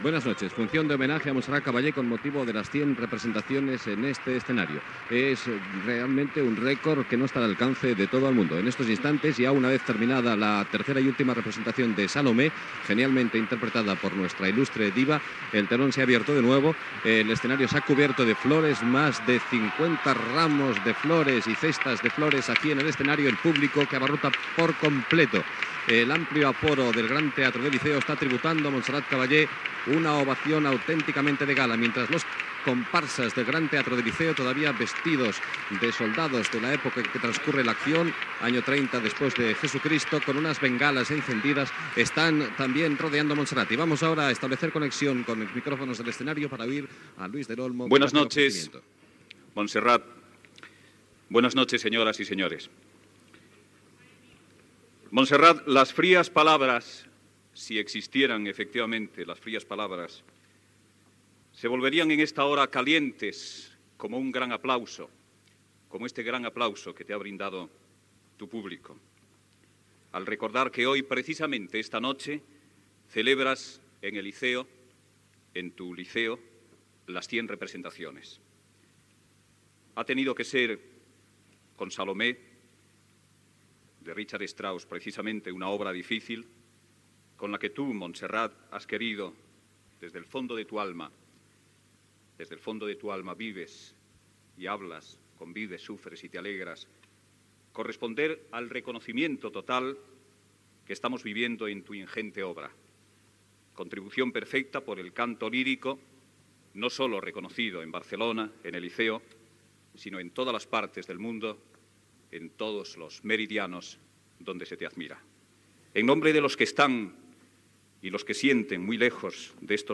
Fragment d'una transmissió des del Teatre del Liceu. Aplaudiments al final de l'obra "Salomé" i homenatge a Montserrat Caballé en haver fet la seva actuació número 100. Paraules de Luis del Olmo i de Montserrat Caballé
Musical